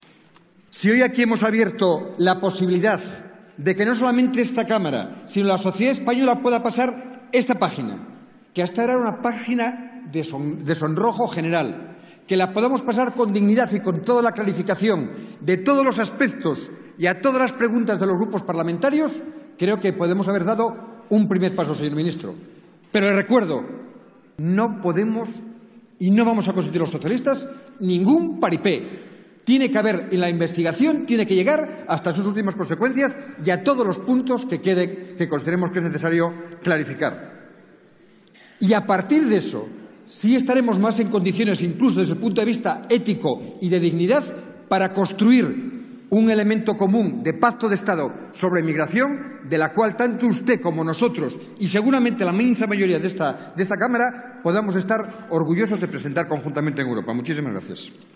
Fragmento de la intervención de Antonio Trevín en la interpelación al ministro del Interior por la muerte de 15 personas intentando llegar a Ceuta desde Marruecos 12/03/2014